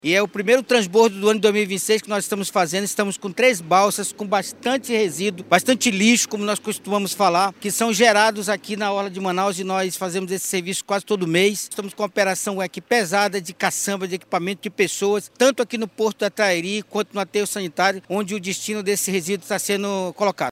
O subsecretário da Secretaria Municipal de Limpeza Pública (Semulsp), Altevir Moreira, afirmou que o transbordo evita que o lixo chegue ao rio Negro e cause danos ao meio ambiente.